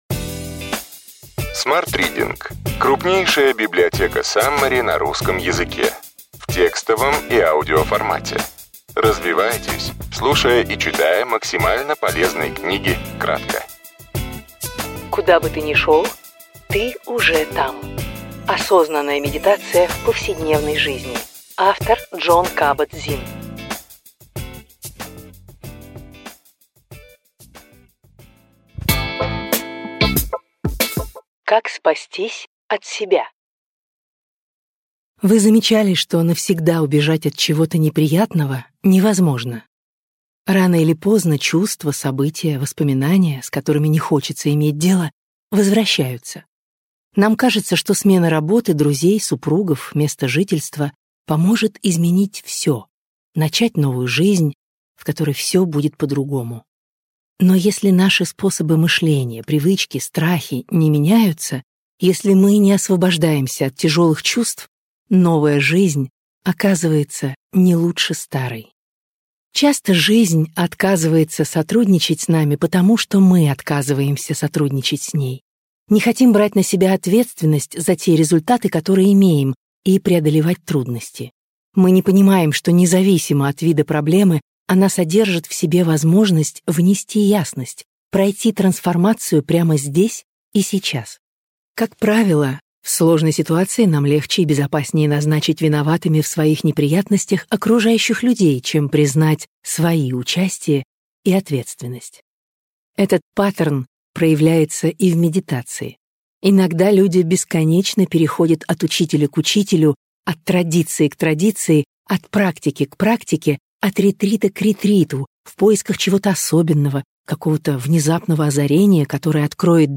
Аудиокнига Ключевые идеи книги: Куда бы ты ни шел, ты уже там. Осознанная медитация в повседневной жизни. Джон Кабат-Зинн | Библиотека аудиокниг